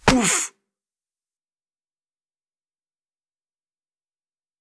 playerHurt2.wav